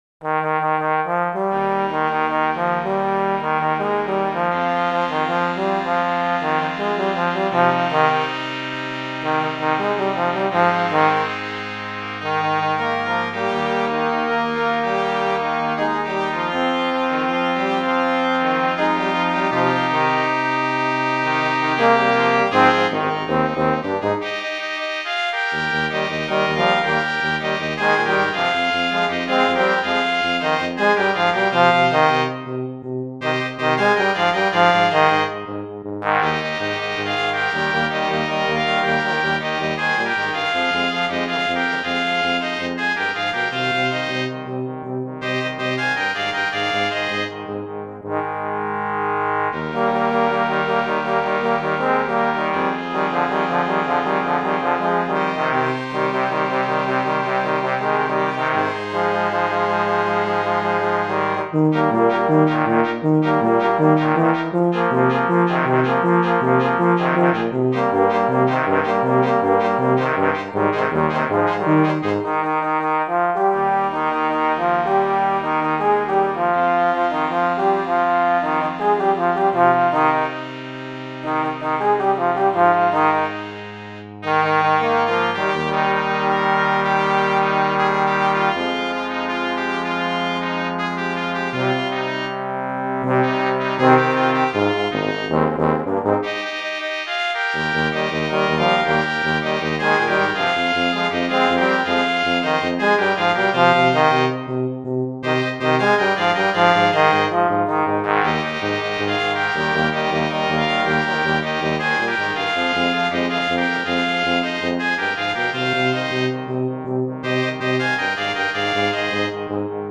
authentisch, druckvoll und (fast) wie das Original.
erstmals für Blasmusik spielbar.
Song – Kleine Besetzungs-Fassung zum Anhören: